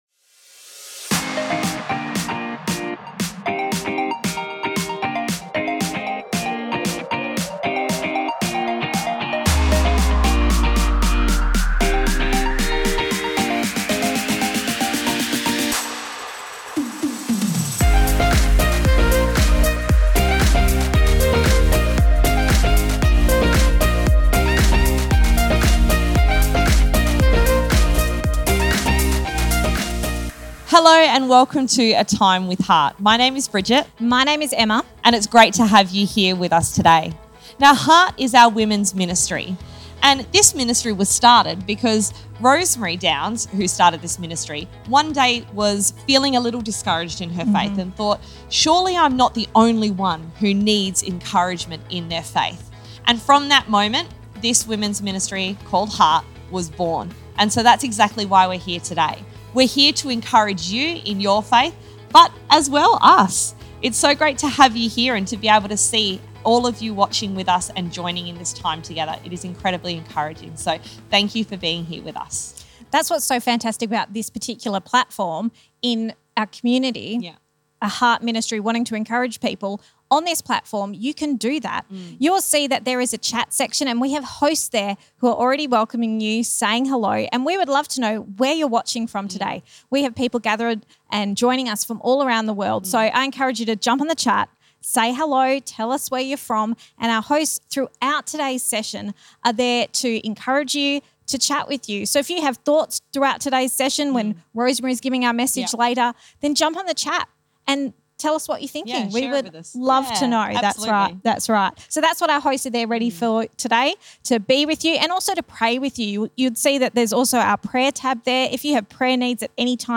We should wait on God to get to know His heart. (This is a recording of our Online Event – A Time with Heart.)